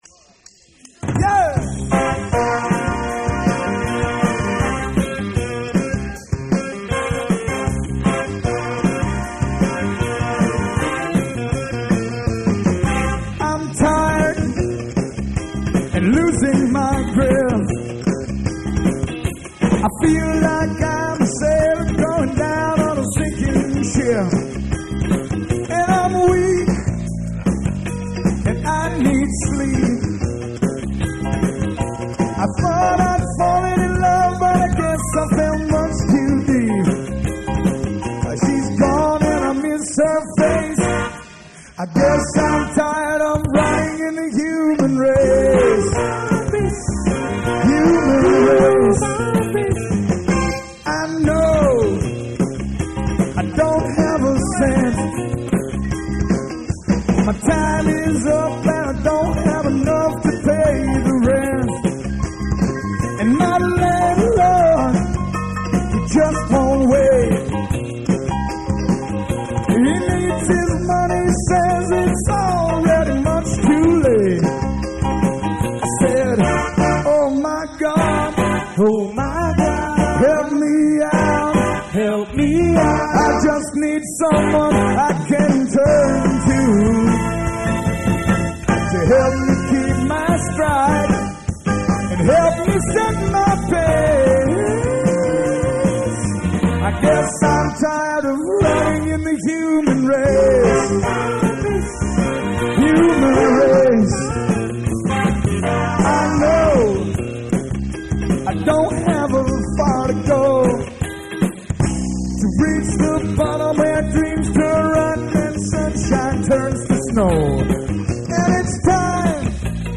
Live & the Jazz Jam
Brewer, Maine.